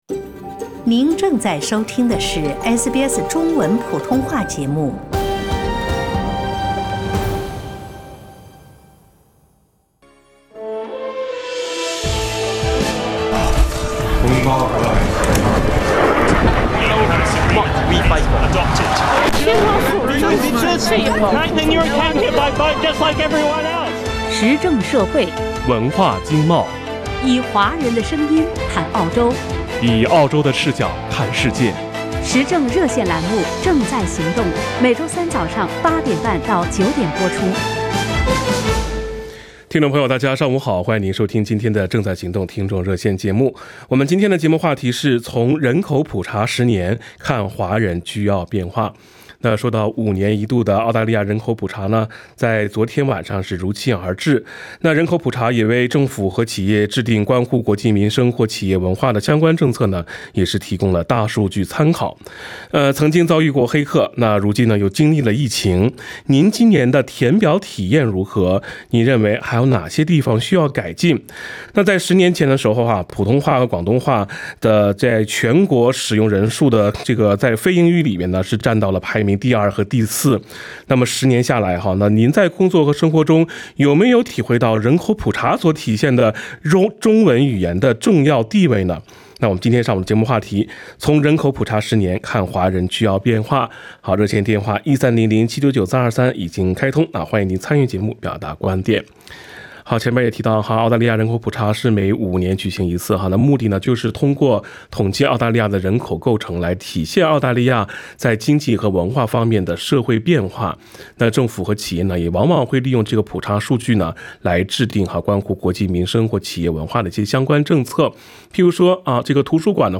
参与《正在行动》热线的听众普遍对人口普查数据存在的积极意义予以肯定，两大超市里亚洲食品货架从有到无且琳琅满目，请官方中文翻译帮忙看病就医从预约难收费高到如今主动提供且完全免费，听众们通过真实生活经历感受到中文越来越重要的社会地位。
LISTEN TO 【正在行动】从人口普查10年看华人居澳变化 SBS Chinese 32:50 cmn 时政热线节目《正在行动》逢周三上午8点20分至9点播出。